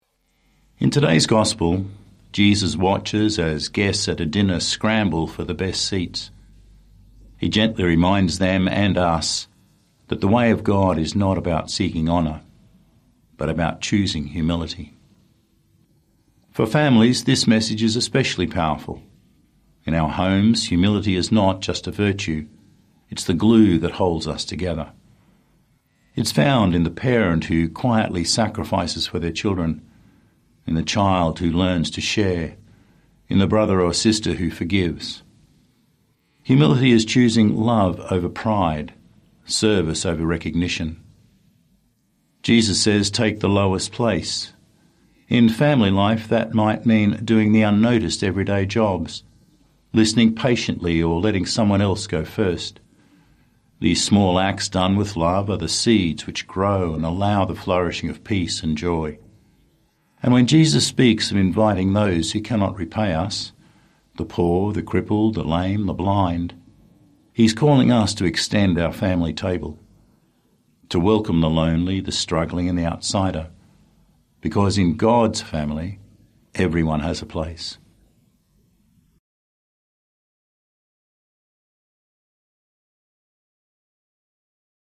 Twenty-Second Sunday in Ordinary Time - Two-Minute Homily